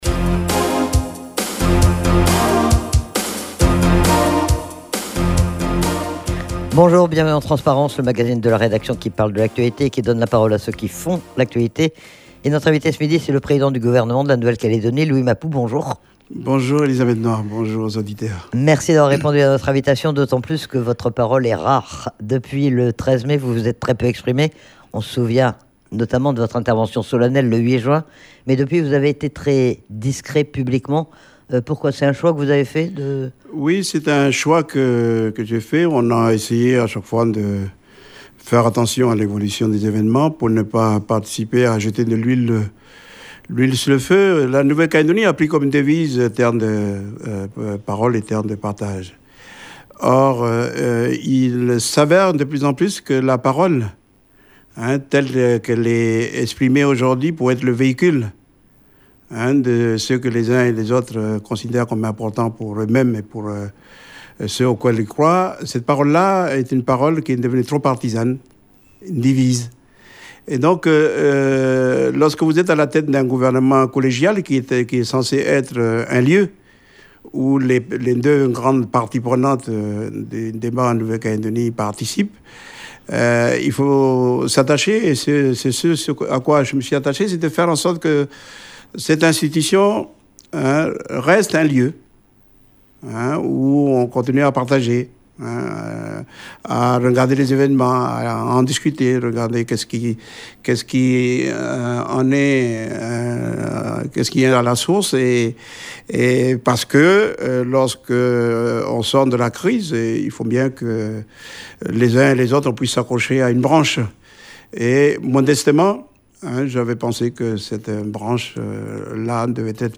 L'occasion de revenir sur la situation économique de la Nouvelle-Calédonie et sur les mesures d'urgence qui ont été mises en œuvre, mais aussi sur le plan S2R préparé par le gouvernement et sur les aides de l'Etat. Le président du gouvernement était également interrogé sur les annonces faites par le Premier ministre lors de sa déclaration de politique générale et sur le calendrier de reprise des discussions.